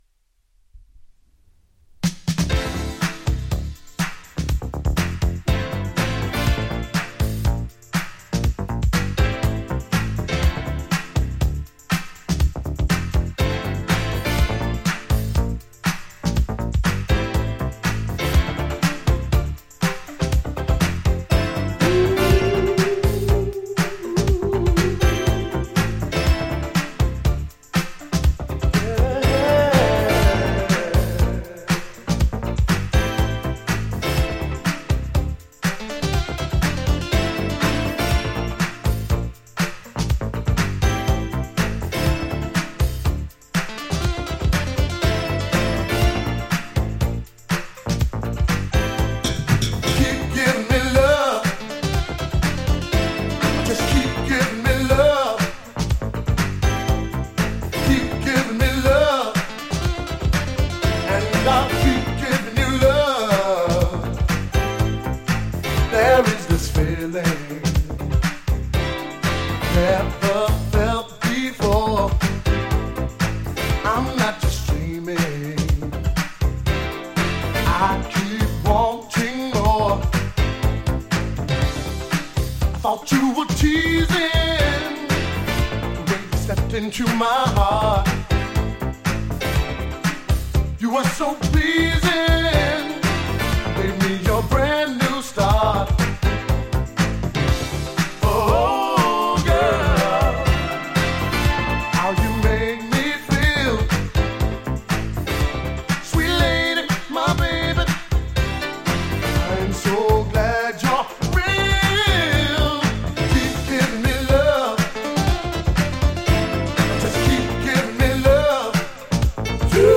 ジャンル(スタイル) FUNK / SOUL / DISCO / ELECTRONIC FUNK